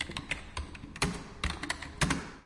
校园。室内 " 黑板
描述：在巴塞罗那的UPF通信校园被写并且抹的黑板。使用内置麦克风使用Zoom H4n录制。
标签： 校园-UPF UPF-CS14 写作 黑板 白垩
声道立体声